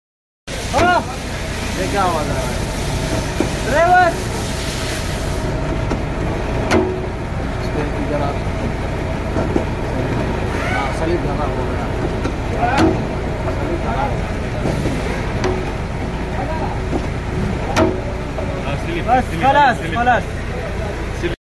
gear shift sound sound effects free download